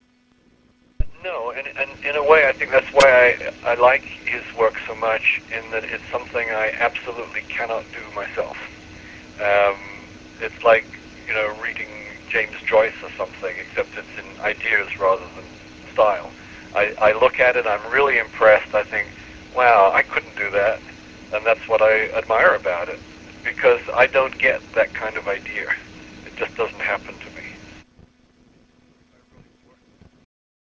Charles Platt Interview, July 4 2000
I had the pleasure of asking him a few questions about his history with New Worlds, thoughts on the genre, publishing industry and fringe sciences as well as talking about his friend and once-collaborator, Barry Bayley. The interview was conducted on phone, from Helsinki to Arizona, on the 4th of July, 2000.